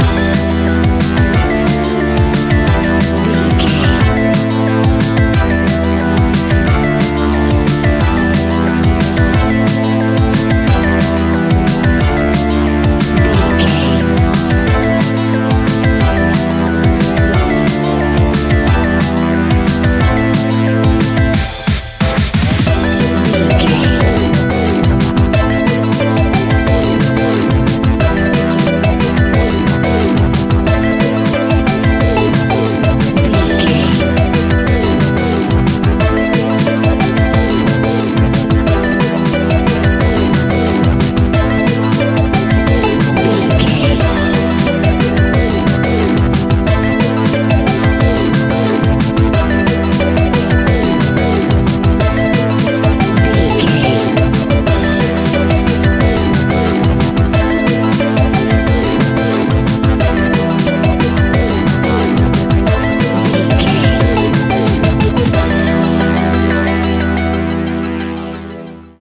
Aeolian/Minor
Fast
hypnotic
industrial
frantic
aggressive
dark
drum machine
synthesiser
sub bass
synth leads